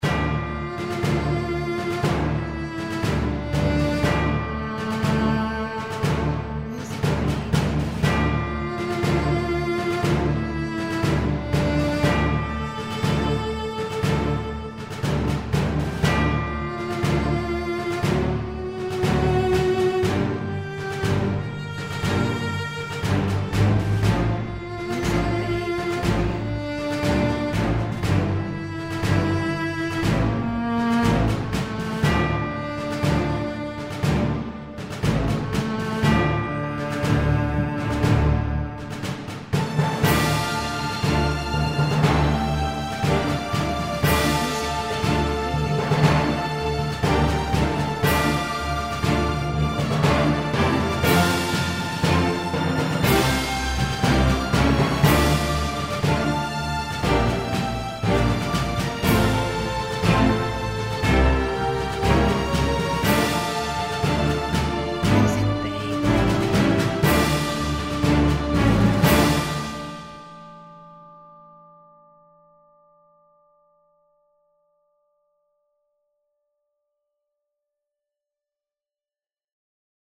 Epic and cinematic category of tags.